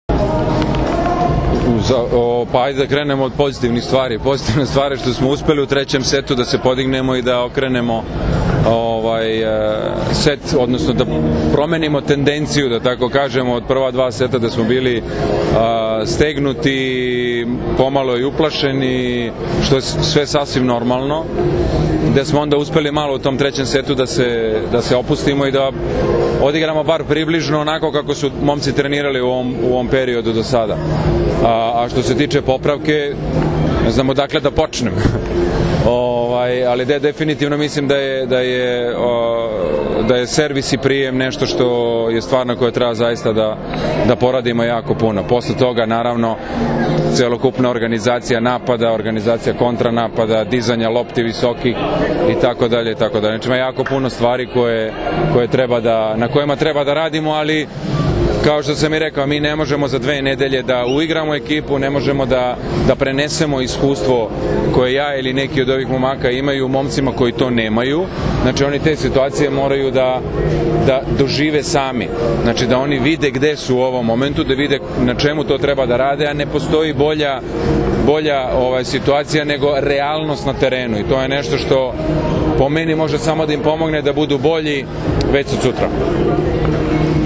Izjava Nikole Grbića